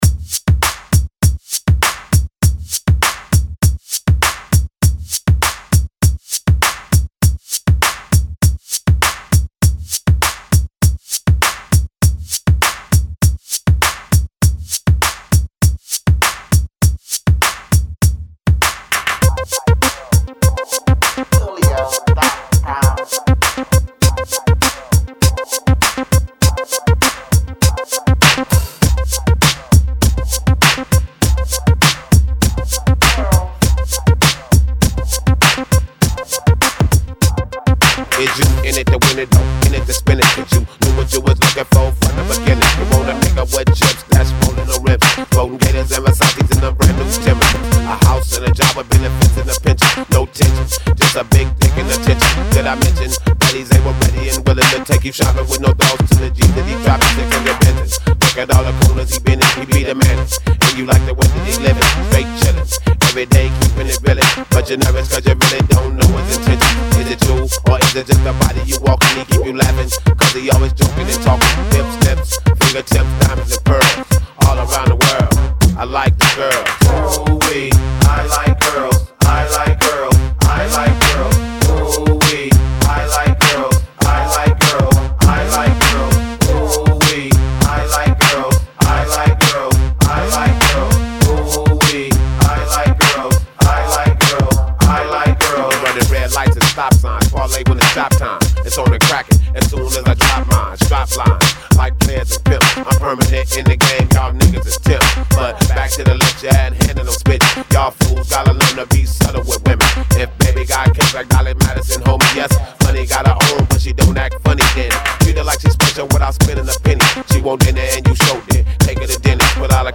Genre: 2000's Version: Clean BPM: 100 Time